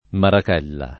[ marak $ lla ]